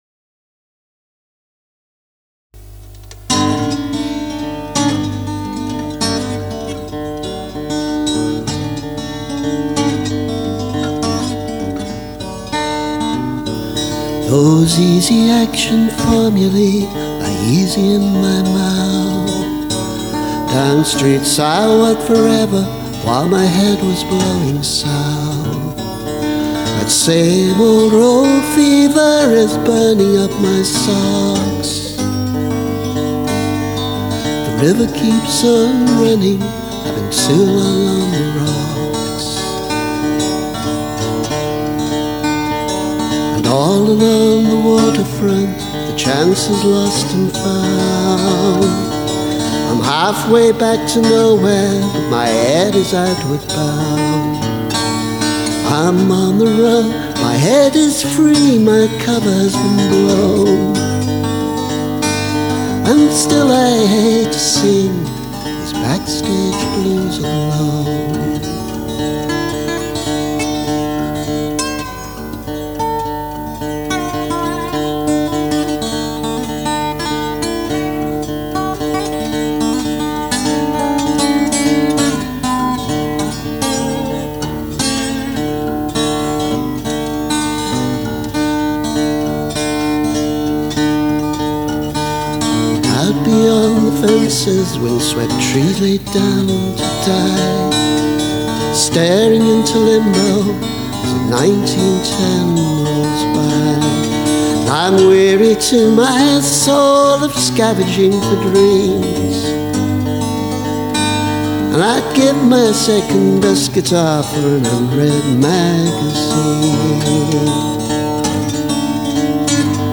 Older version that segues into the blues standard ‘Vestapol’